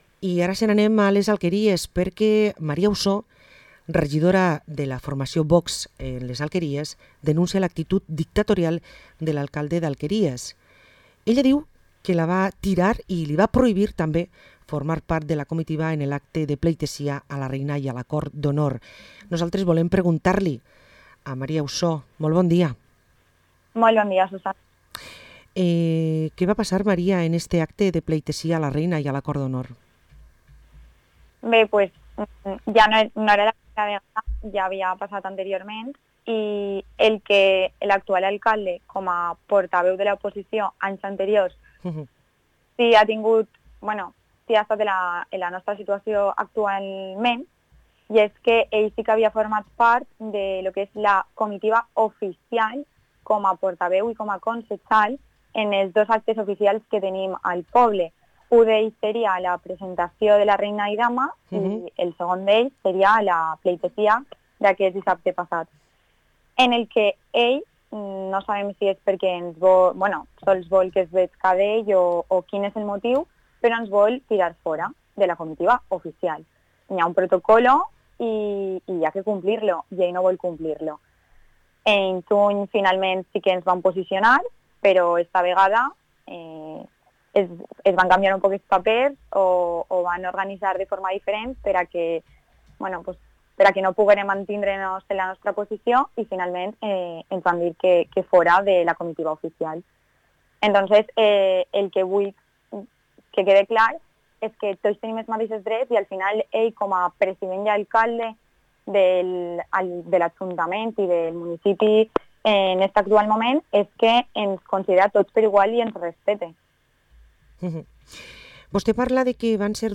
Parlem amb María Usó, regidora de VOX a l´Ajuntament de les Alqueries